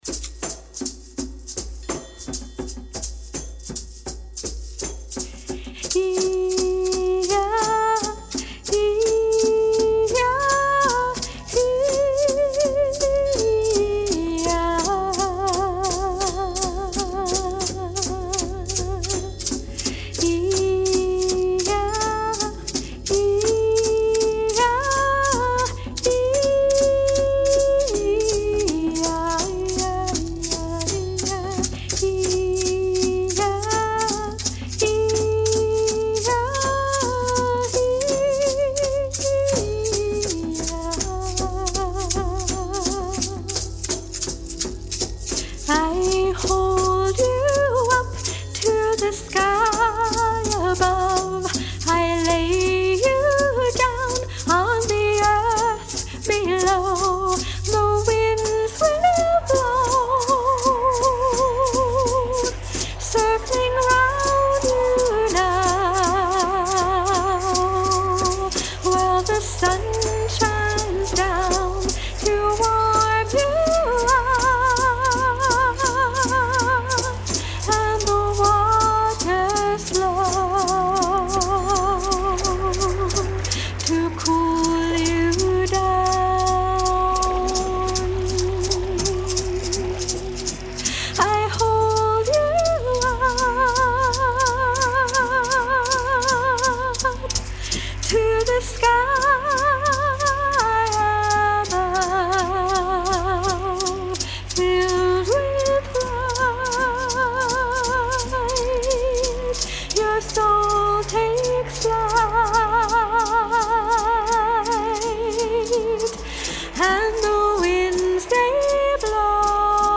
Vocal
Drum
Rattle
Triangle
Tambourine
Soundscape